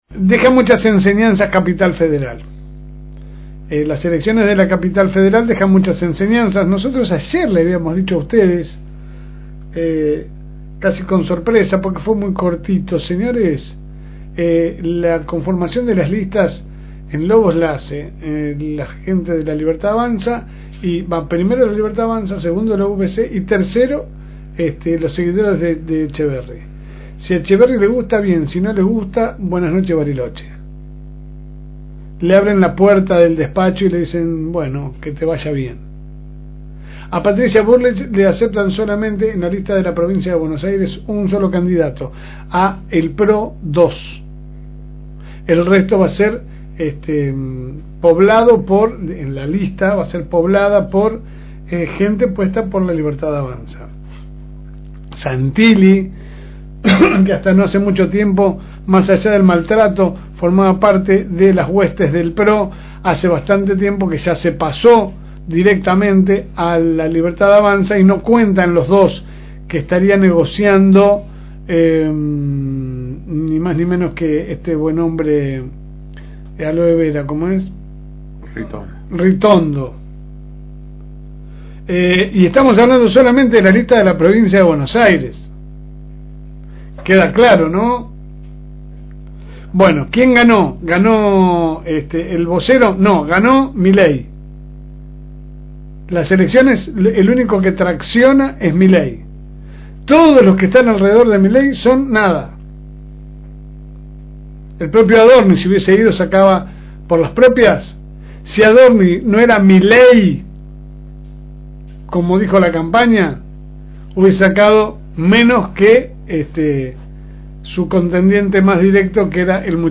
editorial
que sale por el aire de la FM Reencuentro 102.9 de lunes a viernes de 10 a 12 HS.